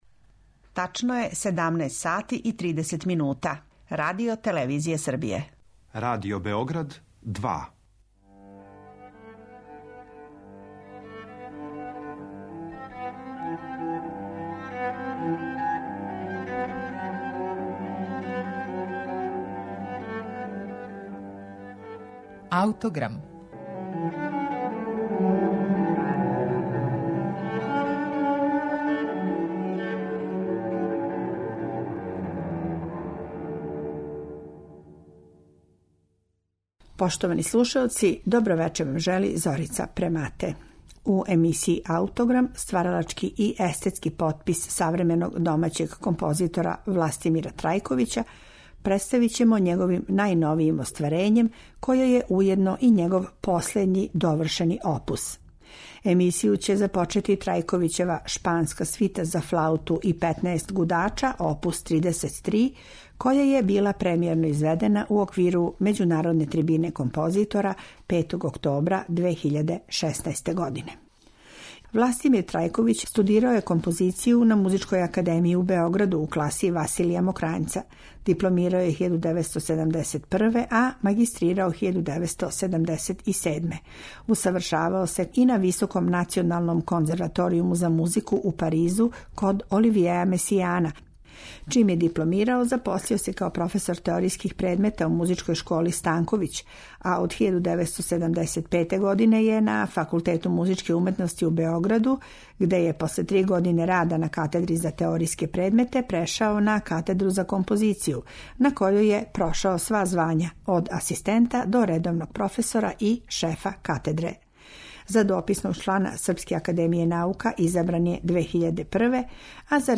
Пар месеци раније, у оквиру Међународне трибине композитора у Београду, премијерно је било изведено његово дело „Шпанска свита" за флауту и гудаче.